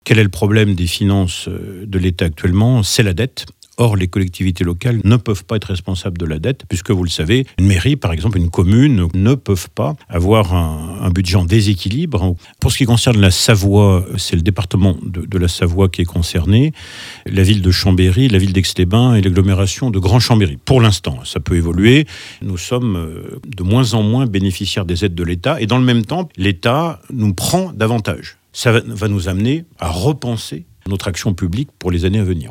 Renaud Beretti, le maire d'Aix-les-Bains décrit cette situation comme une "double peine", combinant la baisse des dotations de l'État depuis 15 ans et la ponction sur les recettes.